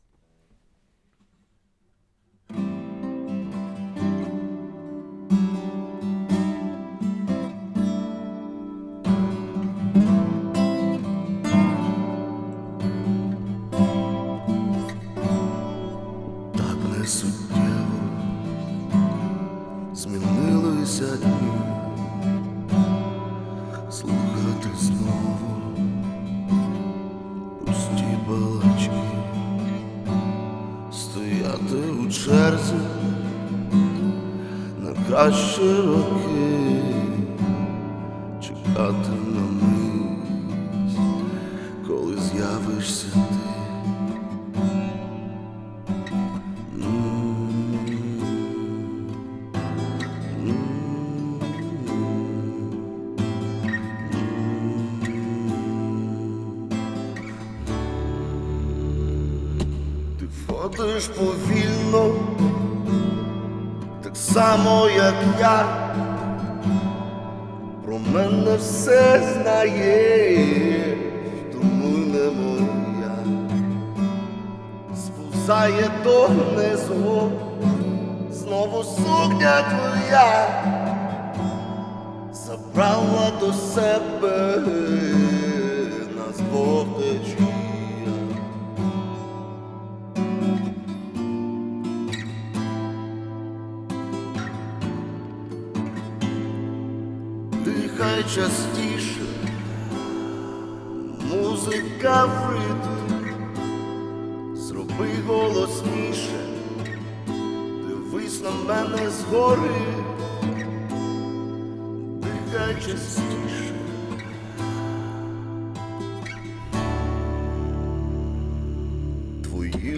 ТИП: Пісня
СТИЛЬОВІ ЖАНРИ: Ліричний
ВИД ТВОРУ: Авторська пісня